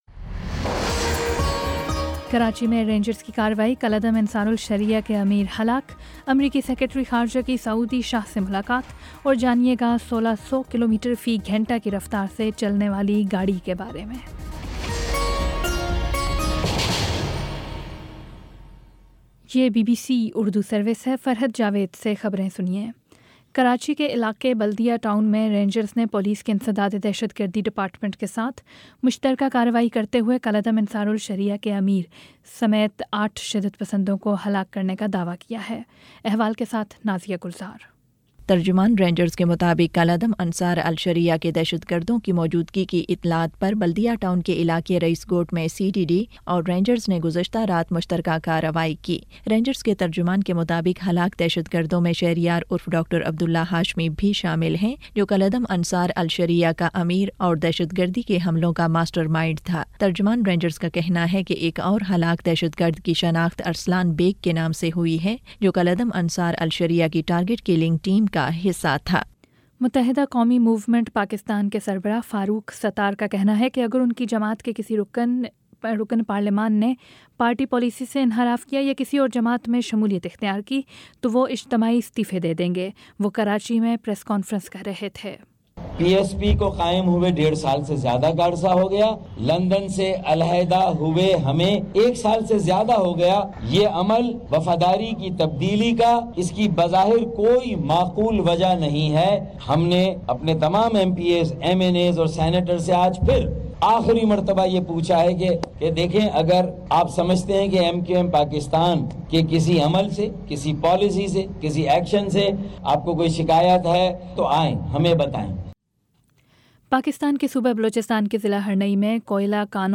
اکتوبر 22 : شام سات بجے کا نیوز بُلیٹن